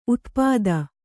♪ utpāda